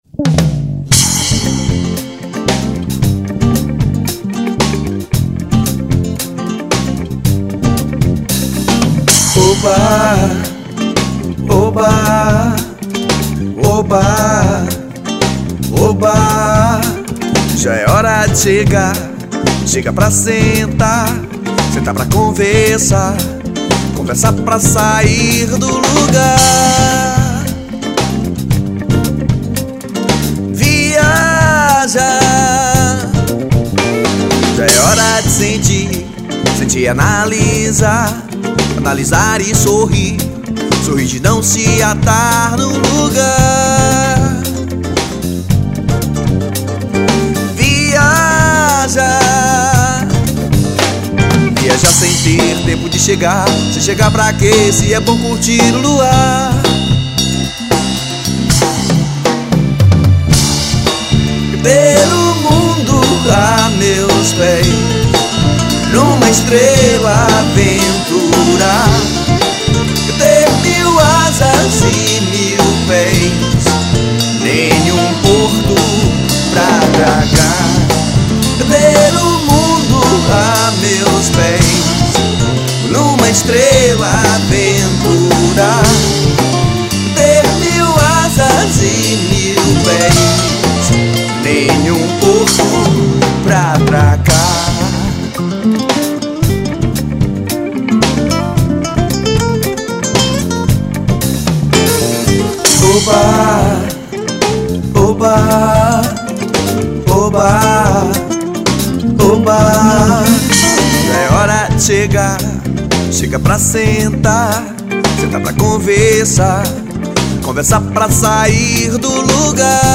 1983   03:38:00   Faixa:     Rock Nacional